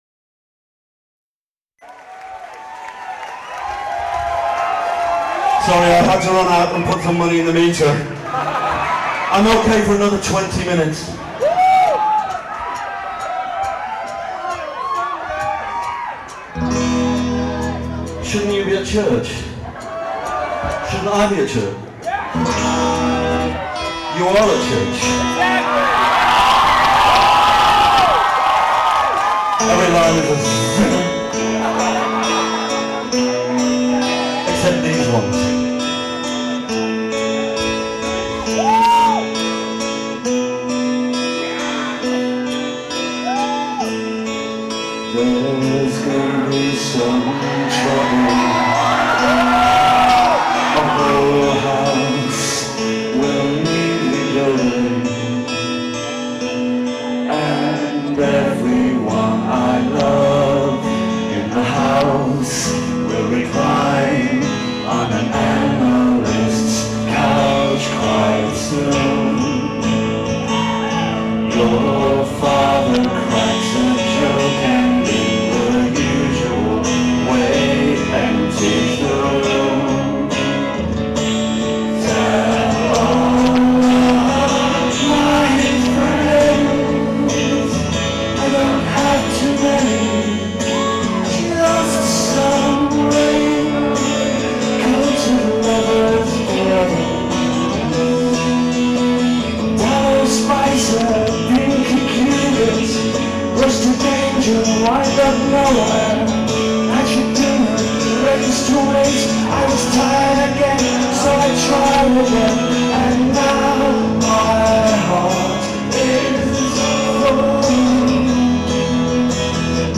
一人沈黙が怖い、音が割れている
雪の中、凍えたボストン、
最前列真ん中から録音した